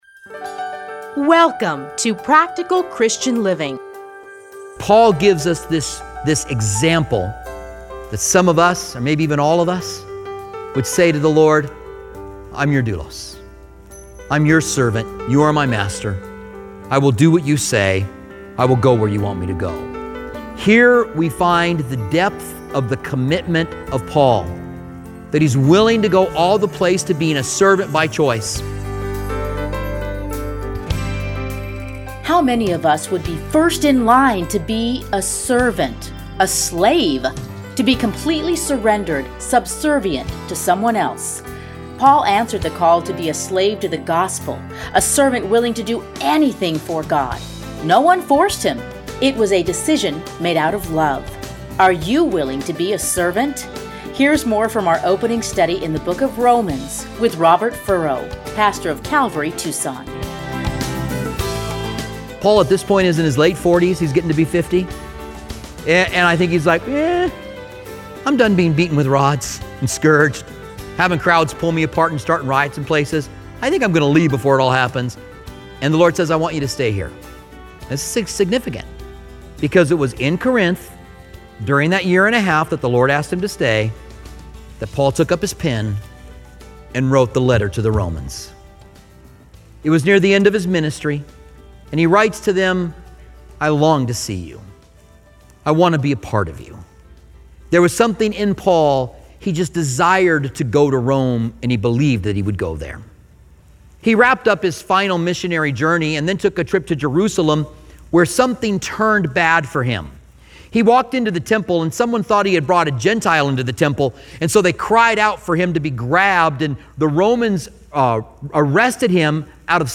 teachings are edited into 30-minute radio programs titled Practical Christian Living. Listen here to his commentary on Romans.